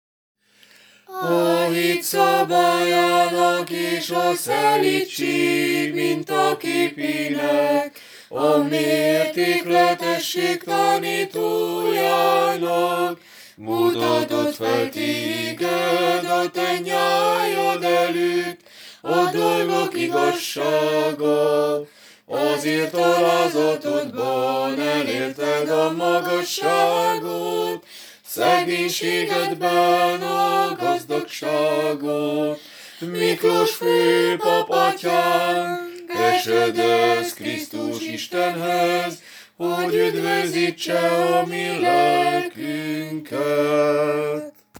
Vasárnapi délelőtti istentisztelet december 6-án, Szegedről
Szent Miklós tropárionja, 4. hang